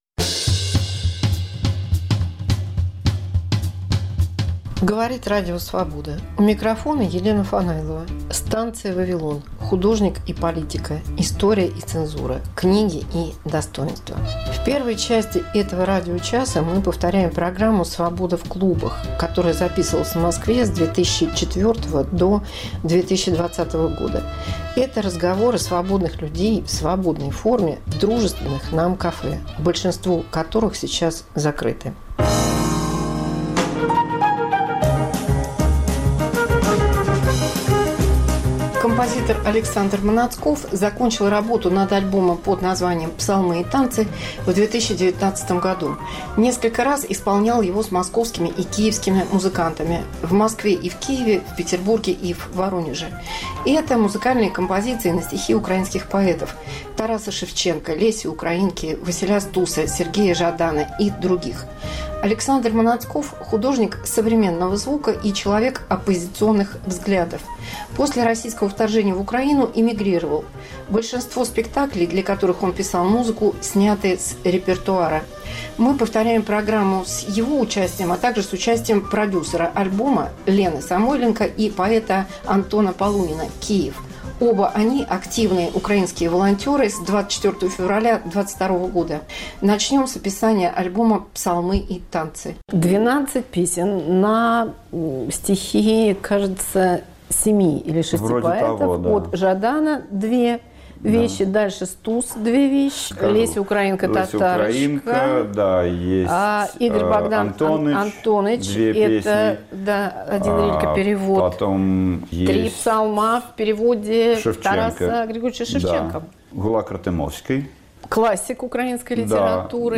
Украинский альбом Александра Маноцкова, 2019 год. 2. Интервью с Верой Полозковой